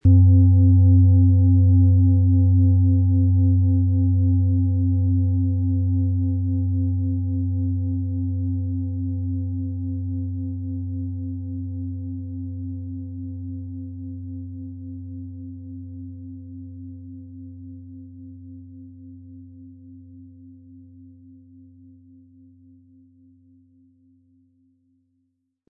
• Tiefster Ton: Biorhythmus Geist
• Höchster Ton: Delfin
Im Sound-Player - Jetzt reinhören hören Sie den Original-Ton dieser Schale.
PlanetentöneDNA & Biorhythmus Geist & Delfin (Höchster Ton)
MaterialBronze